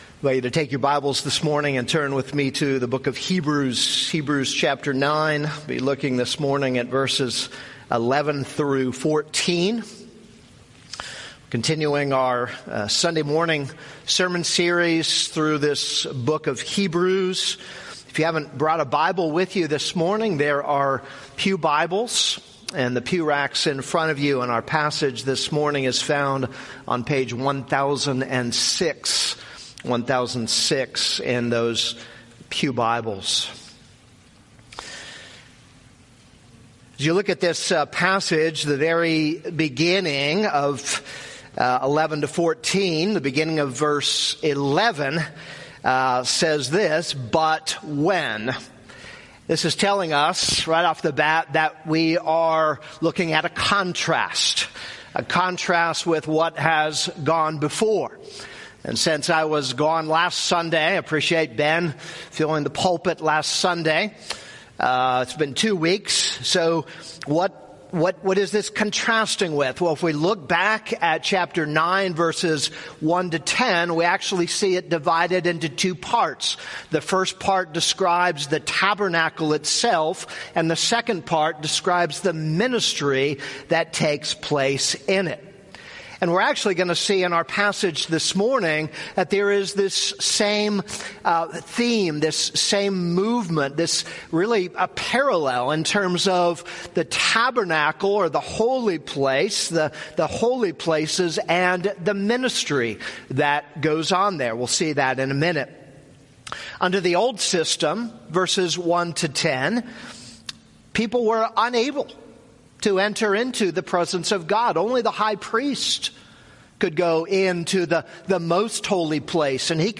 This is a sermon on Hebrews 9:11-14.